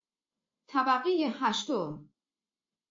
جلوه های صوتی
برچسب: دانلود آهنگ های افکت صوتی اشیاء دانلود آلبوم صدای اعلام طبقات آسانسور از افکت صوتی اشیاء